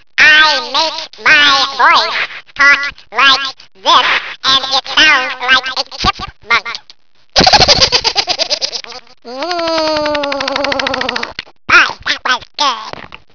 My impression of: "Alvin the Chipmunk."